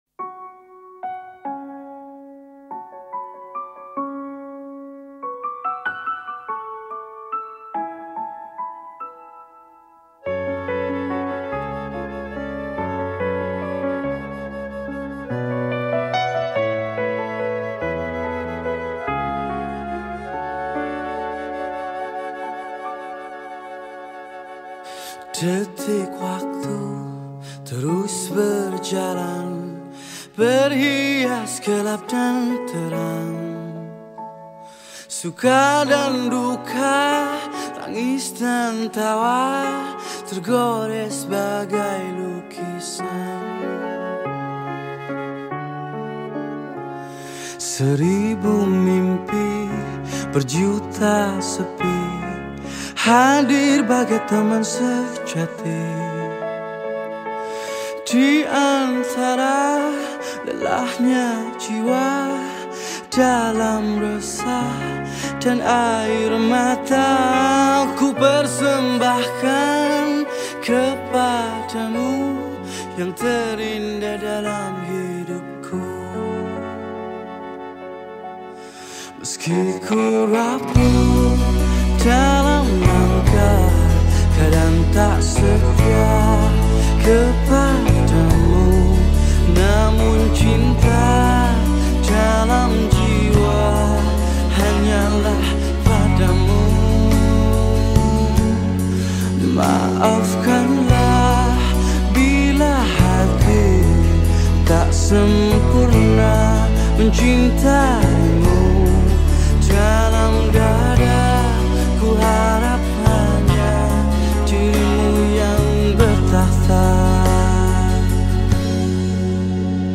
Nasyid Songs
Lagu Nasyid